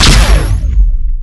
fire_tachyon5.wav